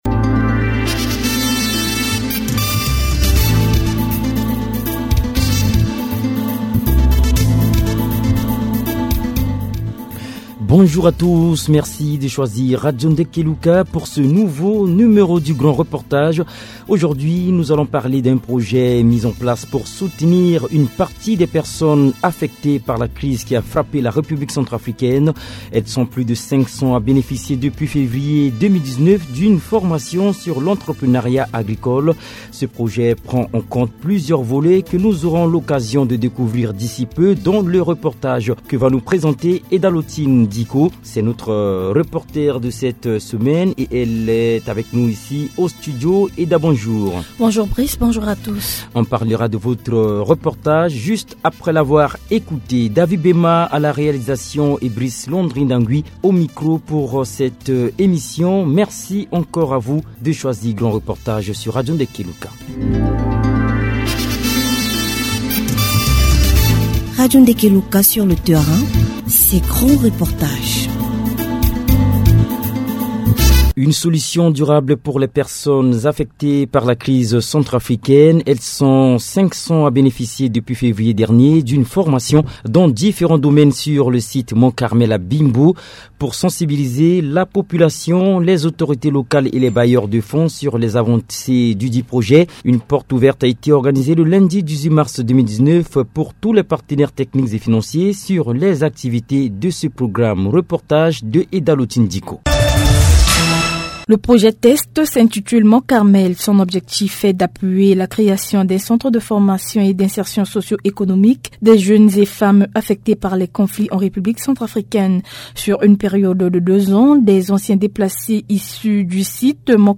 Dans ce reportage, les acteurs et bénéficiaires donnent leurs opinions.